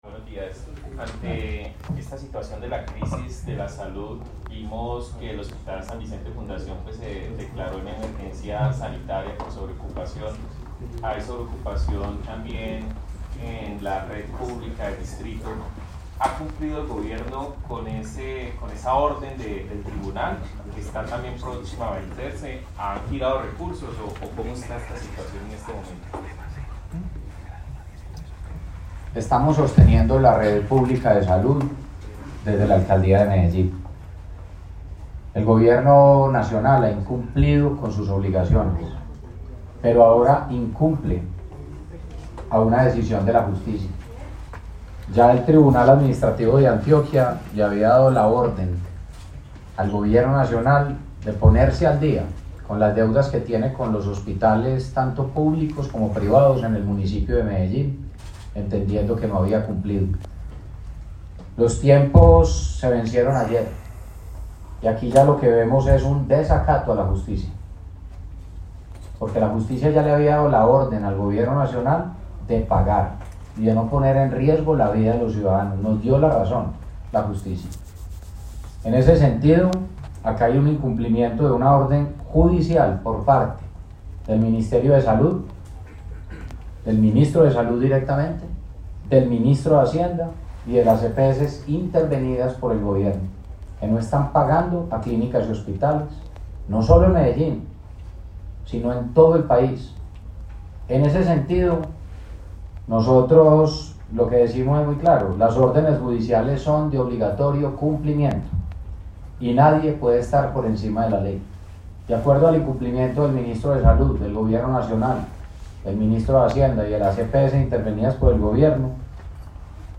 Declaraciones-de-Federico-Gutierrez-alcalde-de-Medellin-04.mp3